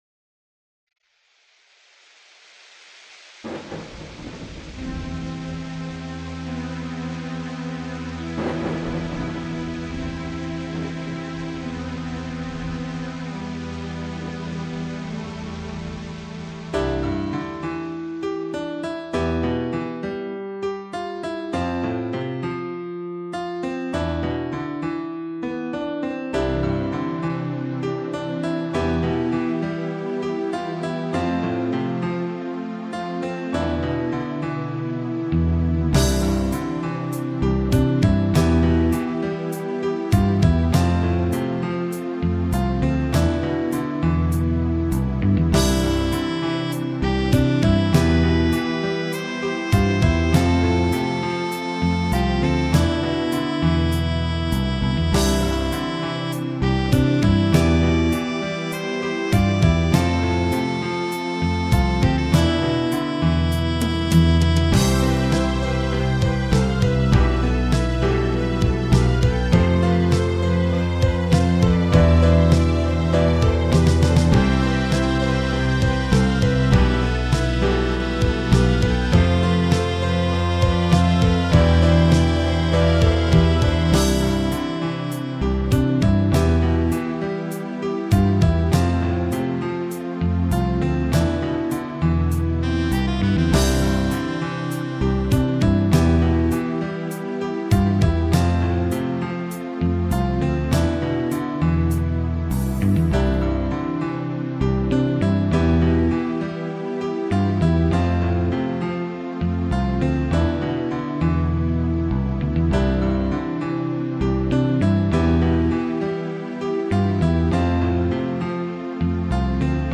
In passato ho composto alcuni brani musicali (completamente strumentali).
Tutti i brani sono stati composti con programmi per computer in formato MIDI.
• Un expander Yamaha MU-50
• Un expander Roland MT-32